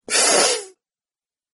Male Minor Pain Gasp